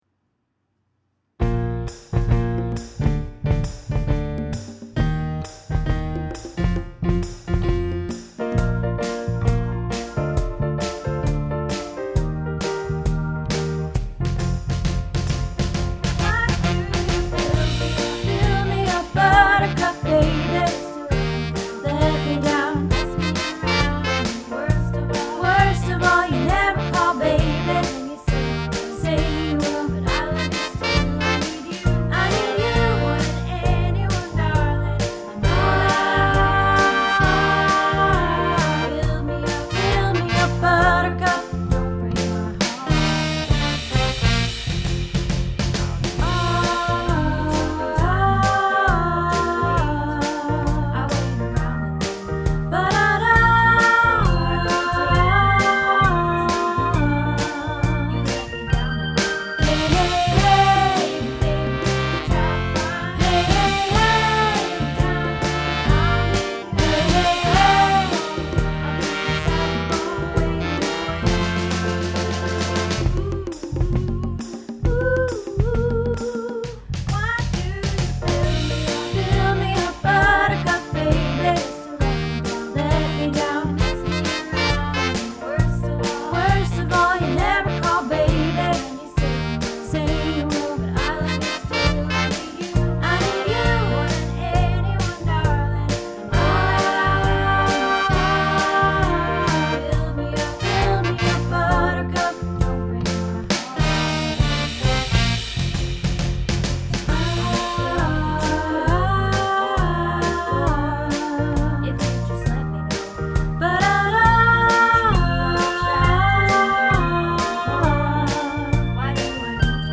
Build Me Up Buttercup – Soprano | Happy Harmony Choir